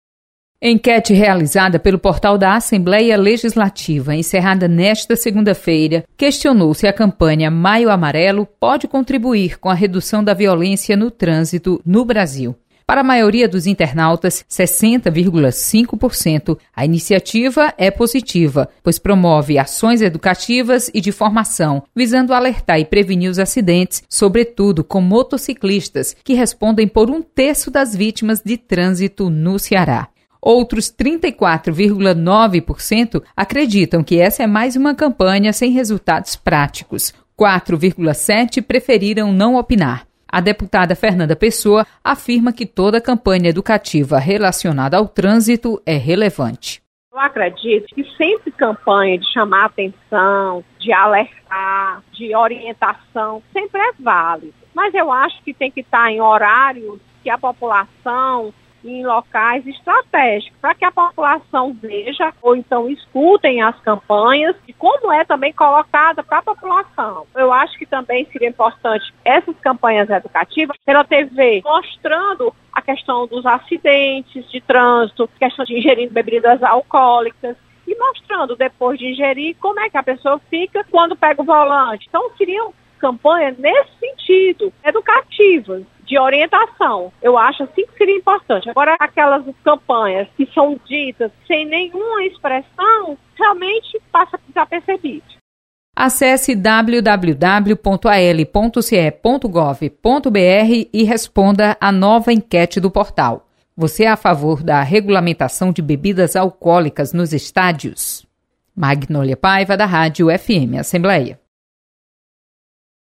Enquete